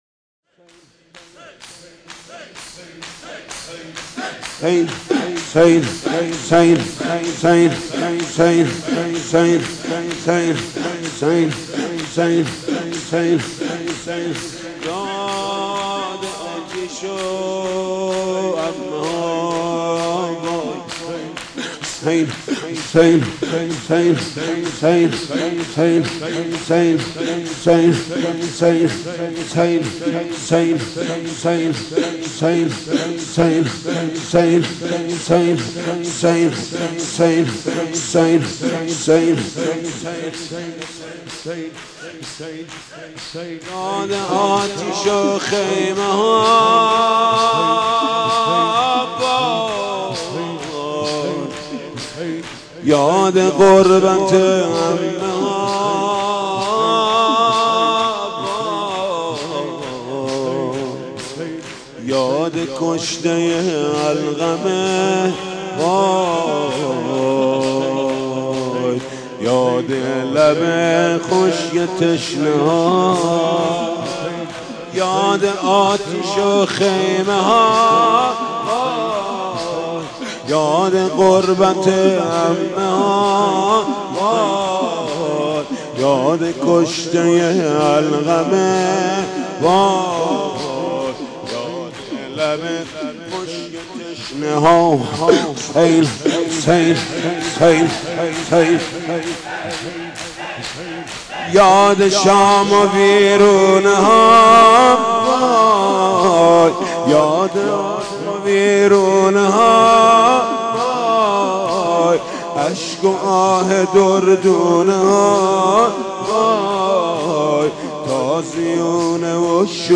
«شهادت امام صادق 1396» شور: یاد لب خشک تشنه ها